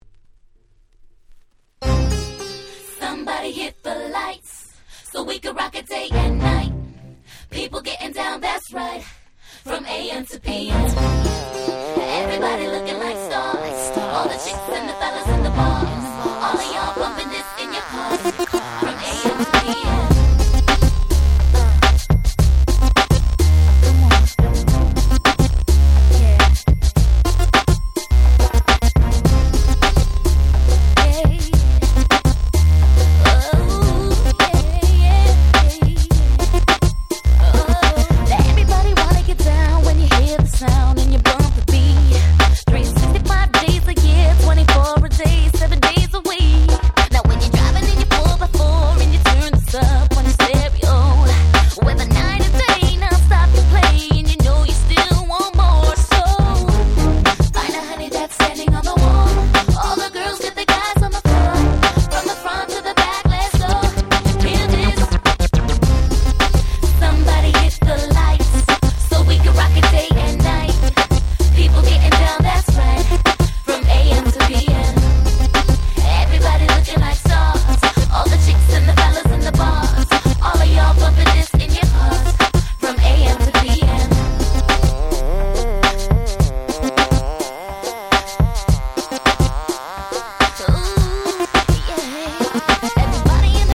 ※一部試聴ファイルは別の盤から録音してございます。
01' Smash Hit R&B !!
キャッチーなサビが抜群な彼女のデビューシングル！